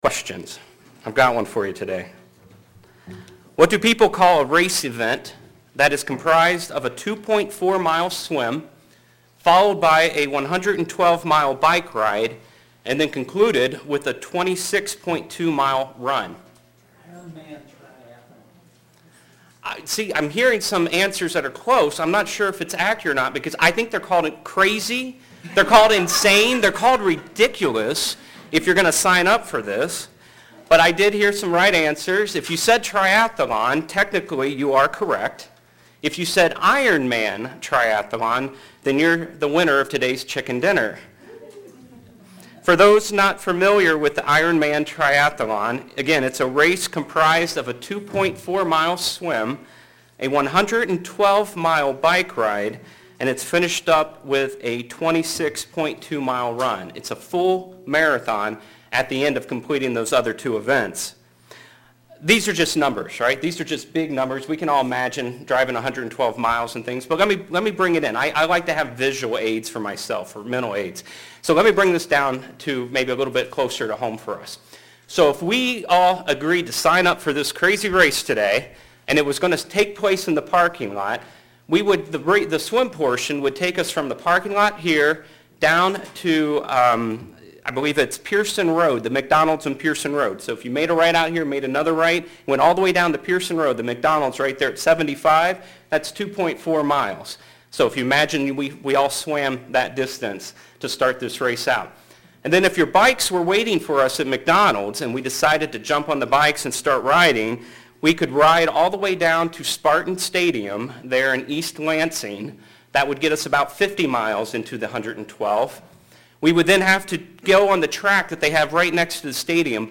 How do we use this transition to continue running our race set before us? sermon Transcript This transcript was generated by AI and may contain errors.